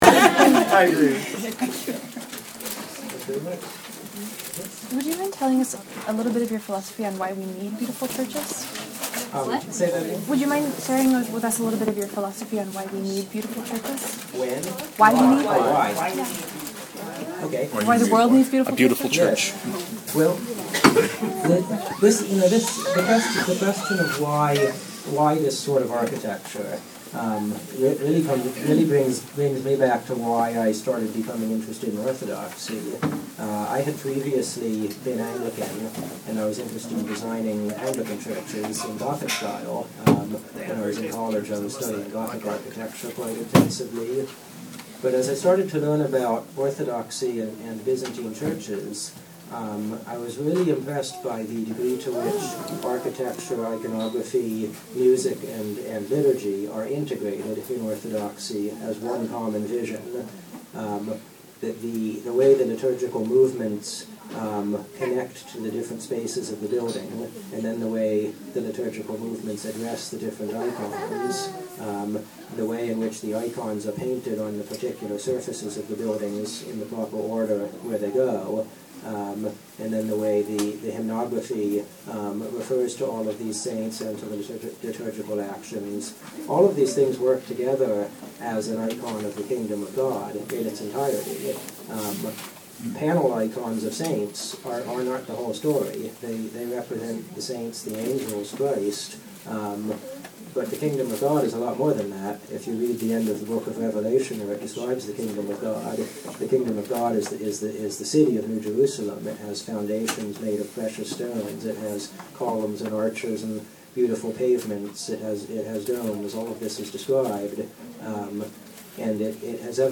this is the best answer to the question I have ever heard, and I’m really glad that I was recording his presentation for other reasons.